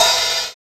DMA CYM RZ1.wav